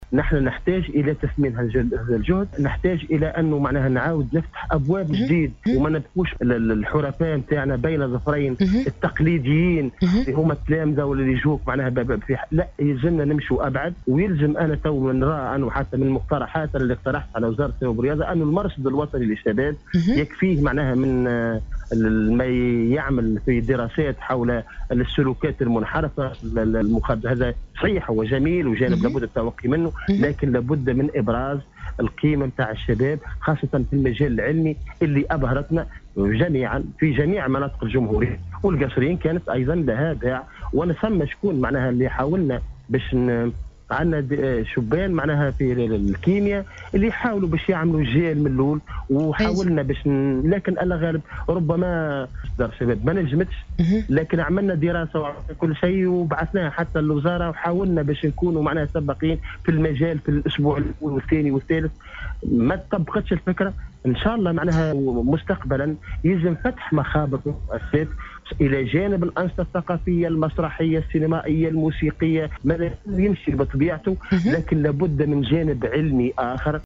في اتصال باذاعة السيليوم أف أم